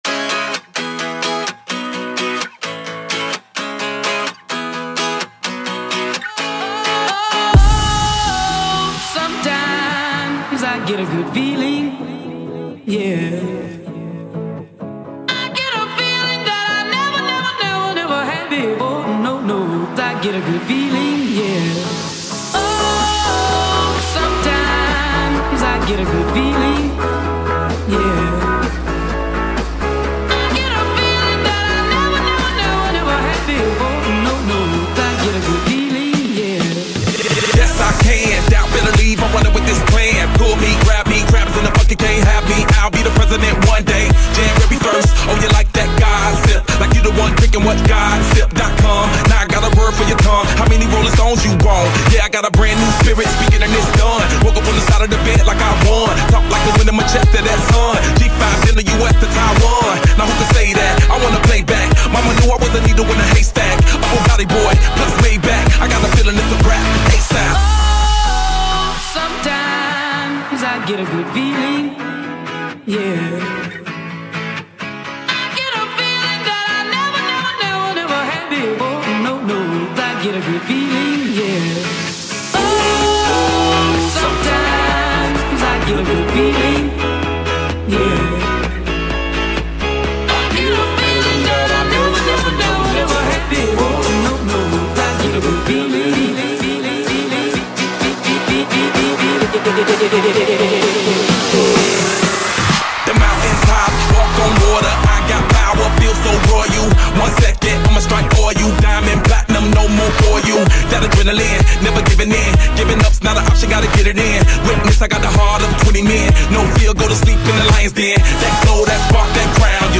is an American rapper, singer, and songwriter.